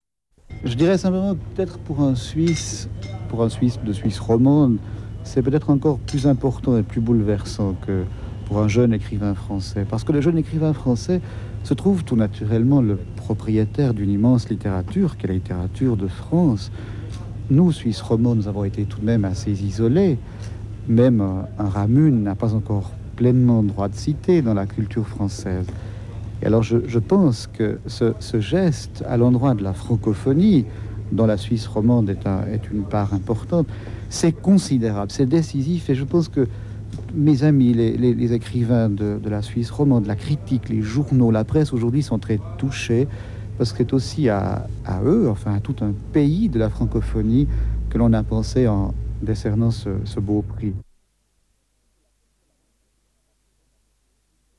Interview de Jacques Chessex à Paris
interview radio de Jacques Chessex